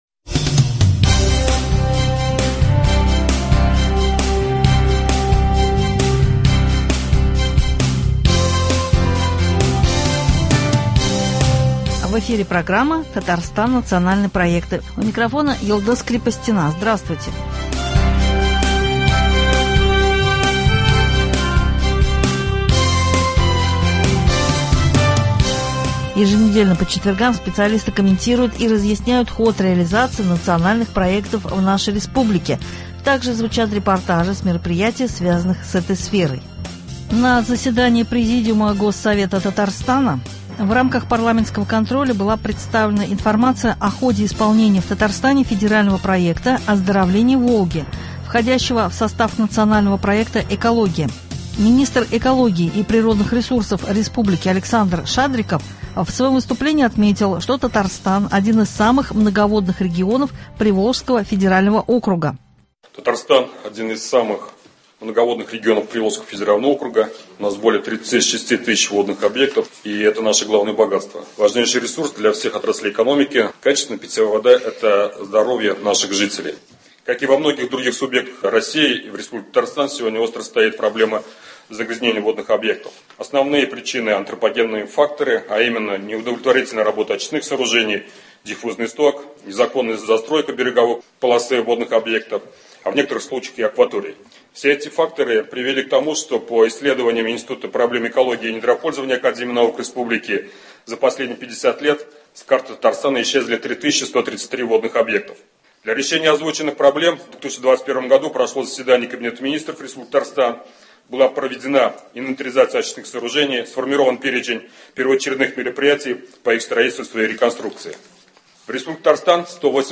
Еженедельно по четвергам специалисты комментируют и разъясняют ход реализации Национальных проектов в нашей республике. Также звучат репортажи с мероприятий, связанных с этой сферой.
Далее в нашем эфире – беседа с депутатом ГД от Татарстана Айратом Фарраховым.